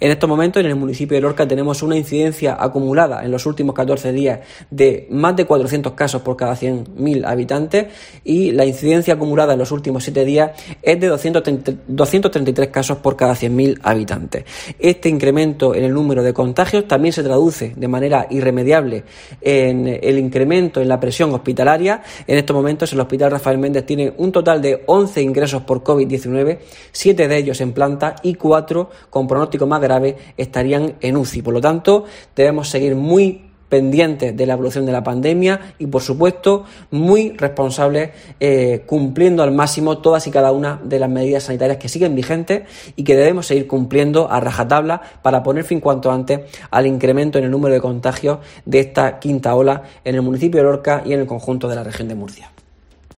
José Ángel Ponce, concejal Sanidad del Ayto Lorca